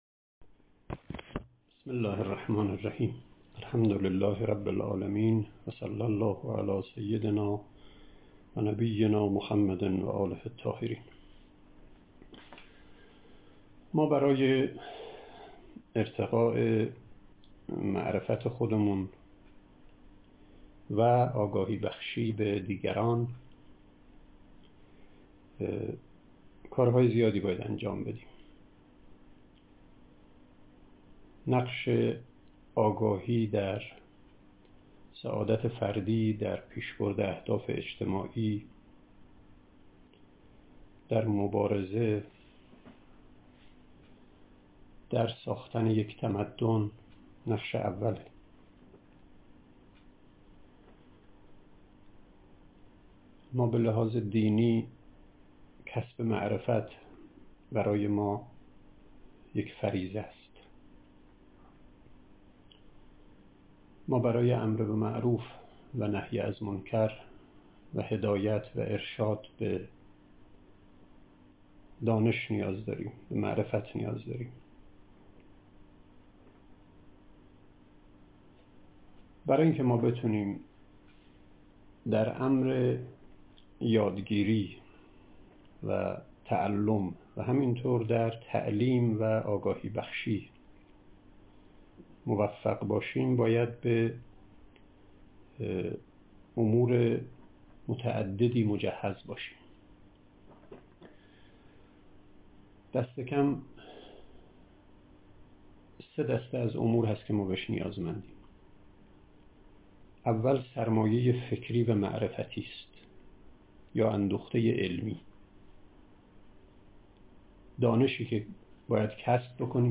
“آنچه همه ما باید بدانیم” عنوان یک سخنرانی